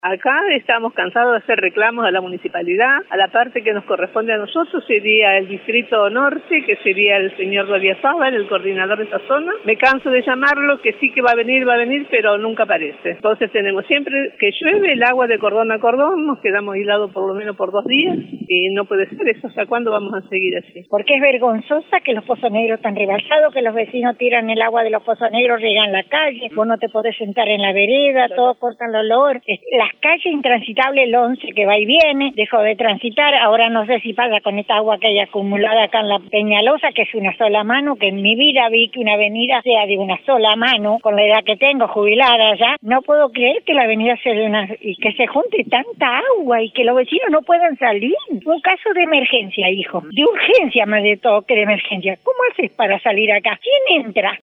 La palabra de los vecinos